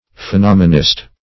Phenomenist \Phe*nom"e*nist\, n. One who believes in the theory of phenomenalism.